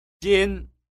jīn